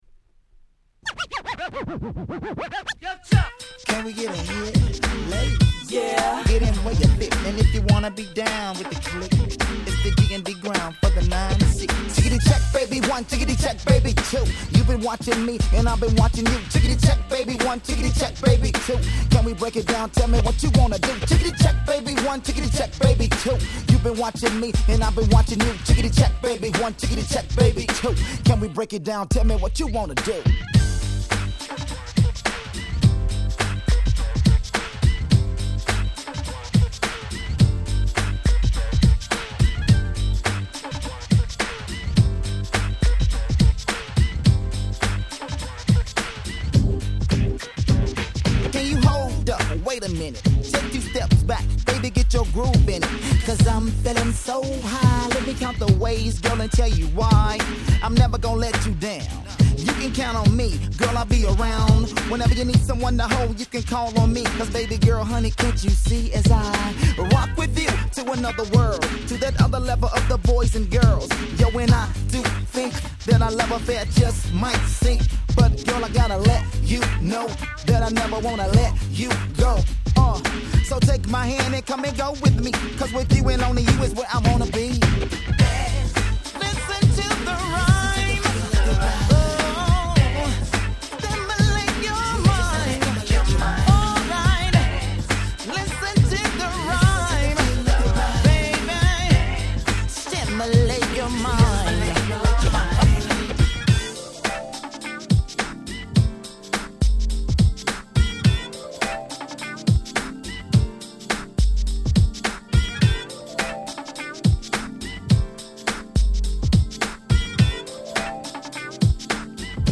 97' Nice Japanese R&B !!
国産ユニットながら、全編英語での歌詞、サウンドも国外物に引けを取らない素晴らしい出来！